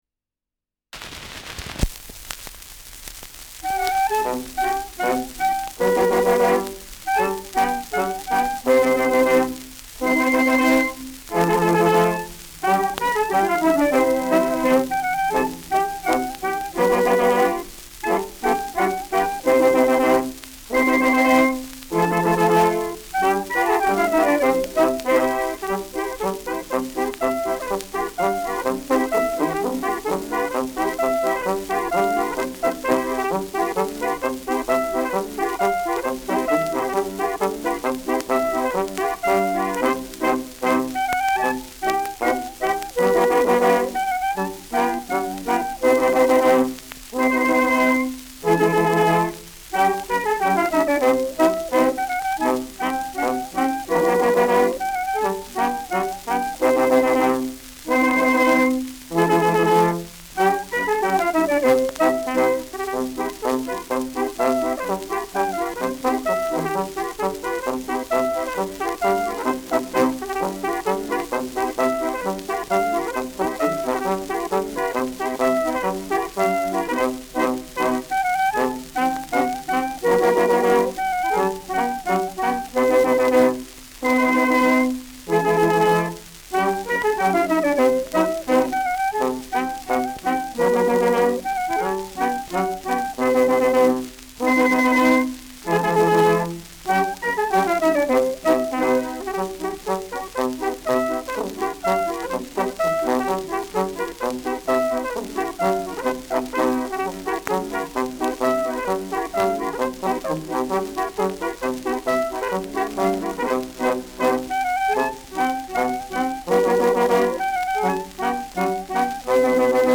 Schellackplatte
Stärkeres Grundrauschen : Gelegentlich leichtes bis stärkeres Knacken
Stadelheimer Salon-Kapelle (Interpretation)